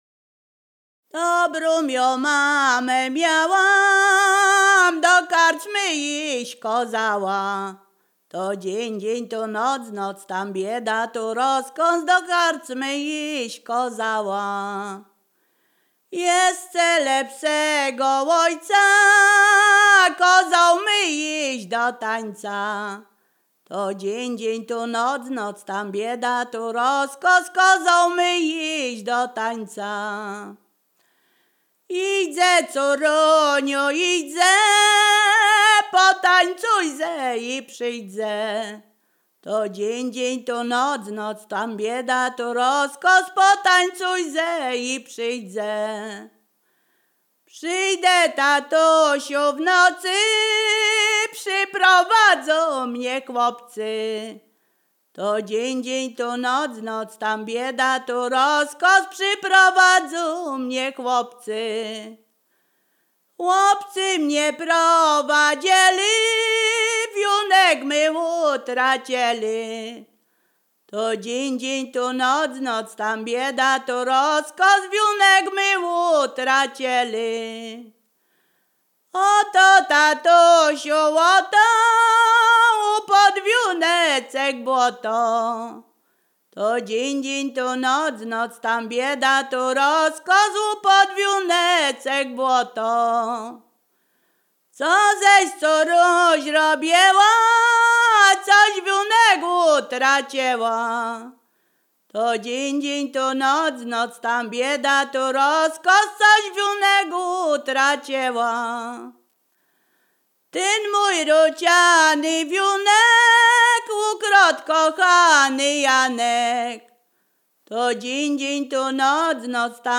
Ziemia Radomska
Liryczna
liryczne miłosne obyczajowe